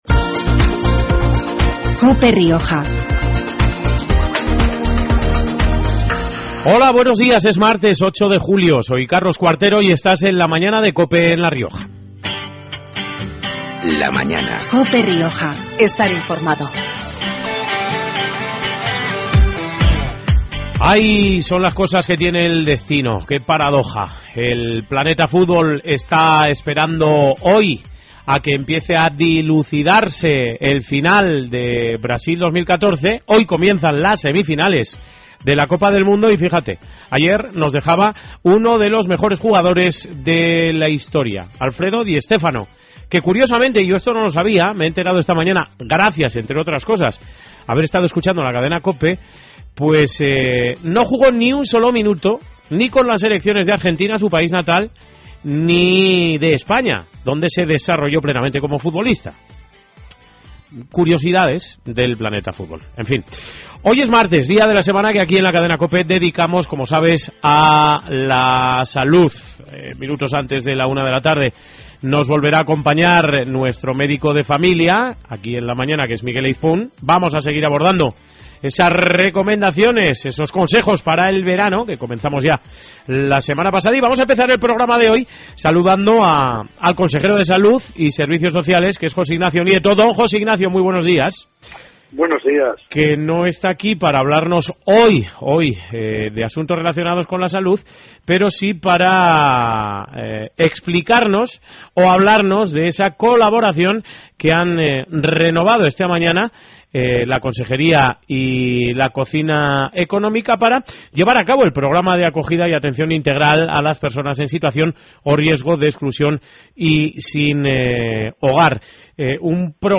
La entrevista fue muy entretenida y dinámica, y espero que sea de vuestro agrado. Hablamos del amor, de las vacaciones, de cómo cuidar lo que tanto nos gusta…